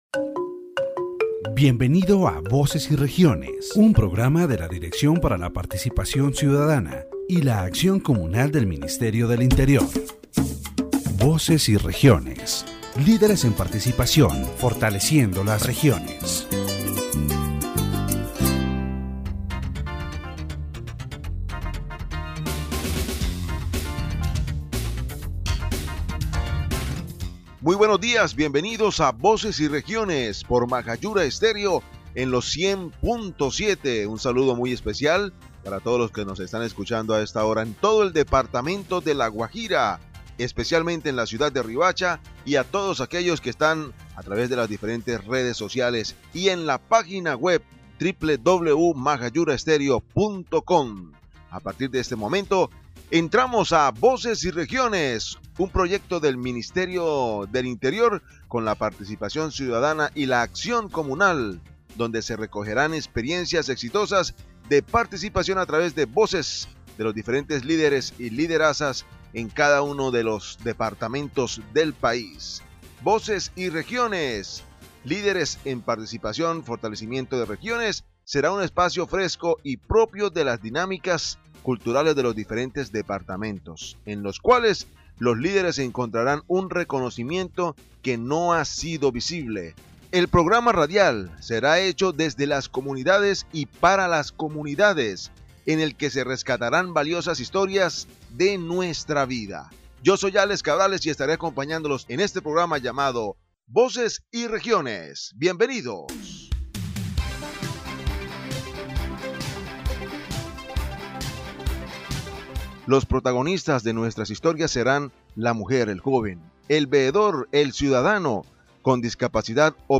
"Voces y Regiones" is a radio program that promotes citizen participation and community strengthening. This edition, broadcast on Majayura Stereo 100.7 FM, highlighted the importance of human rights in La Guajira and the role of indigenous communities in regional development. It emphasized the need to safeguard cultural values, foster inclusion, and make citizen demands more visible to build a more equitable society.